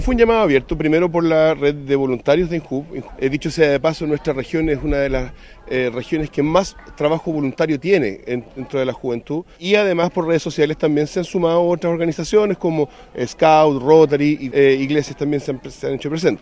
De acuerdo al seremi de Desarrollo Social y Familia, Rodrigo Baeza, la convocatoria a ser parte de esta iniciativa fue abierta y difundida por redes sociales.